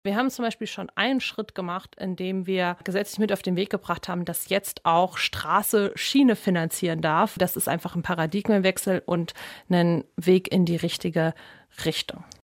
Sommerinterview mit Laura Kraft
Unsere Bundestagsabgeordnete war zu Gast bei Radio Siegen.